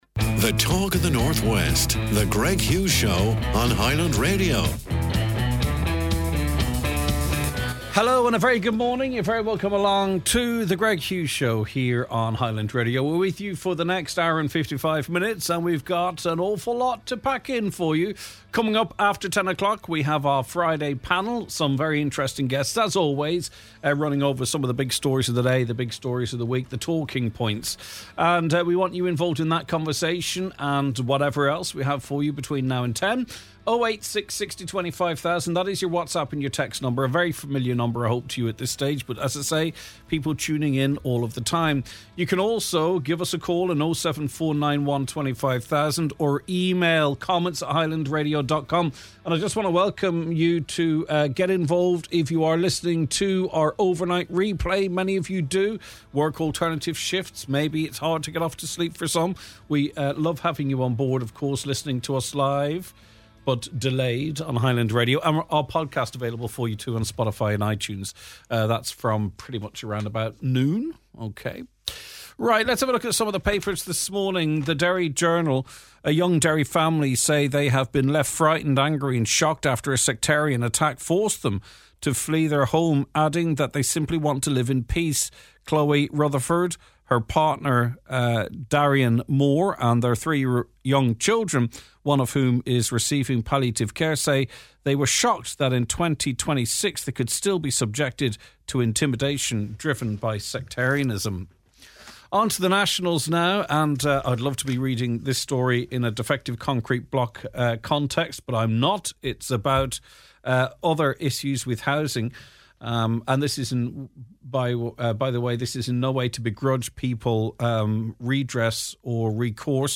Air Rescue for Donegal: Senator Manus Boyle joins us to discuss his urgent call for a dedicated emergency aeromedical service to be based in the county.